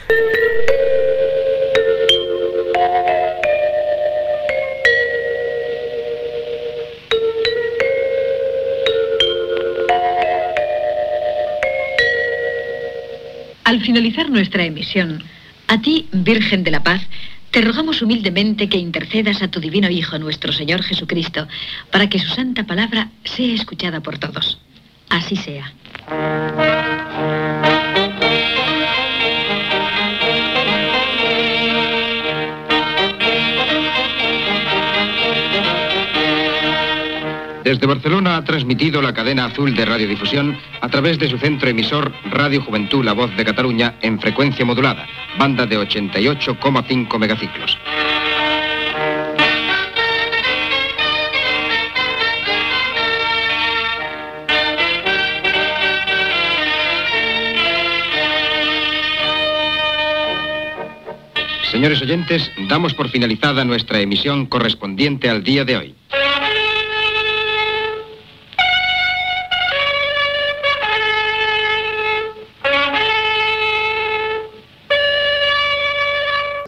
Tancament d'emissió. Sintonia, oració, freqüència i identificació.